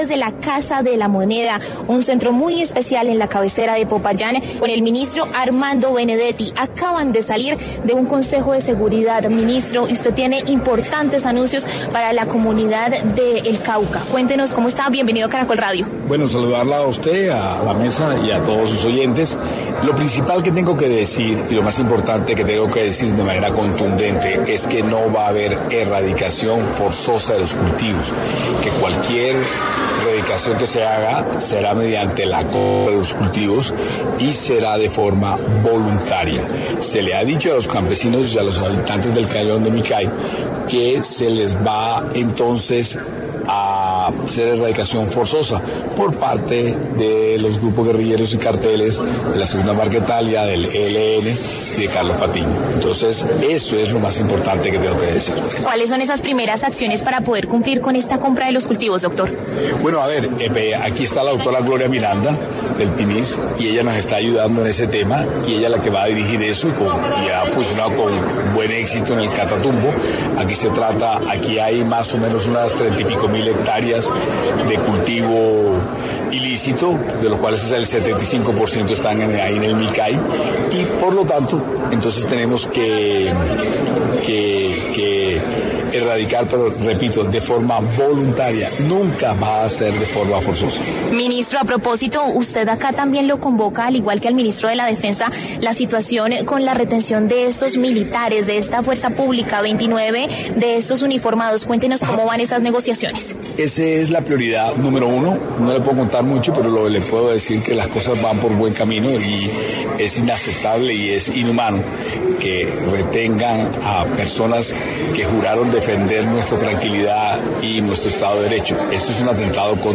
En entrevista con el Noticiero del Mediodía de Caracol Radio el ministro del Interior, Armando Benedetti anunció desde el Cauca que, no va a haber erradicación forzosa de los cultivos.